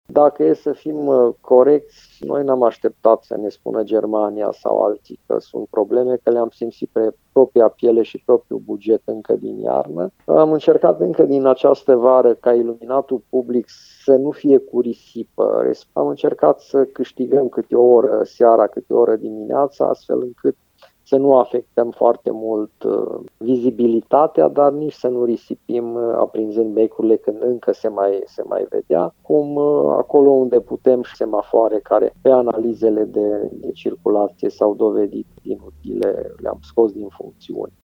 Primarul Călin Bibarţ a explicat că pornirea iluminatului stradal este amânată cu o oră şi se opreşte mai repede cu o oră dimineaţa, iar semafoarele electrice care funcţionau doar pe culoarea galben intermitent au fost oprite.